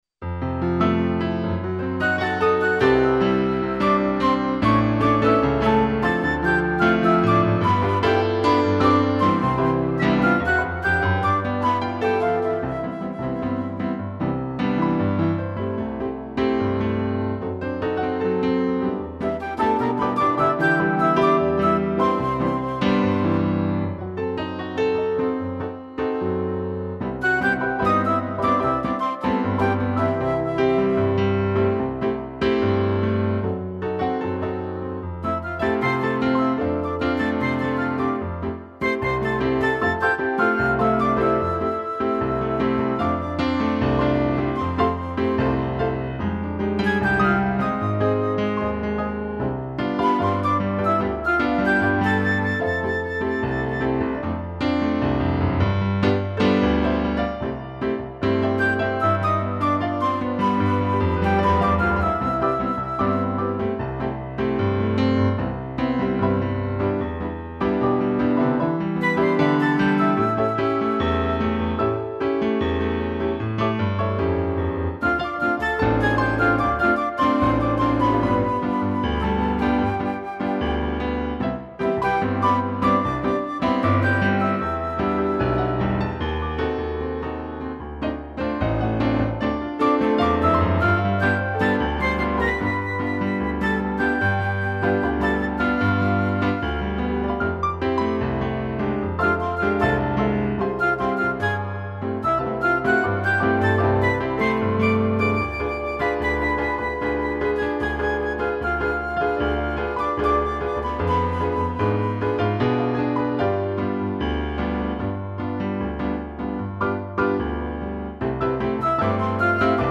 2 pianos e flauta
(instrumental)